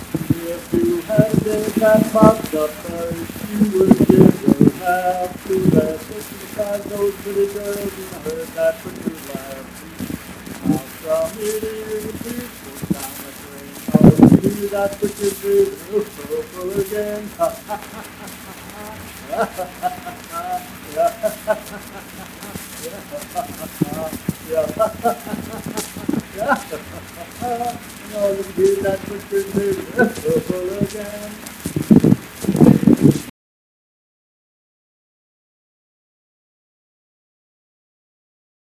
Unaccompanied vocal music performance
Verse-refrain 2(4).
Miscellaneous--Musical
Voice (sung)